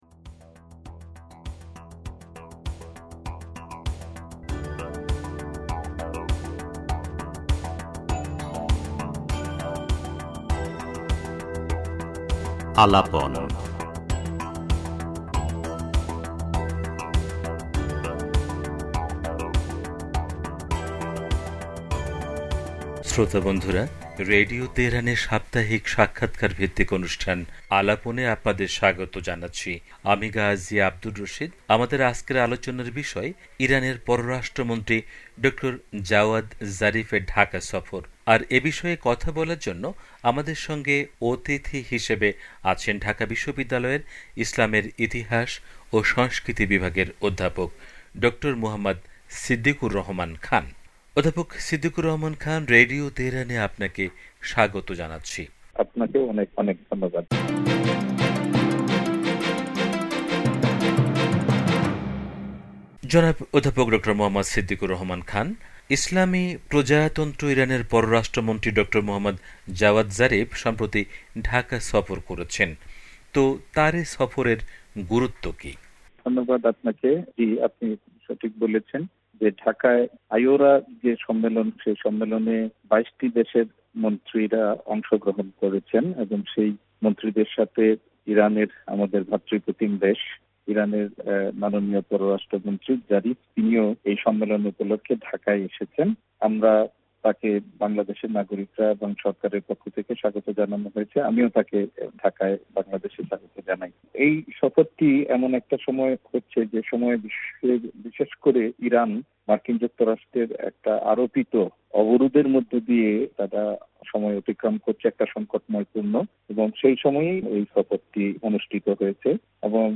ইরানের পররাষ্ট্রমন্ত্রী ড. জাওয়াদ জারিফের ঢাকা সফর অত্যন্ত ইতিবাচক প্রভাব ফেলবে। রেডিও তেহরানকে দেয়া সাক্ষাৎকারে একথা বলেছেন, ঢাকা বিশ্ববিদ্যালয়ের ইসলামের...